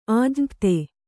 ♪ ājñapte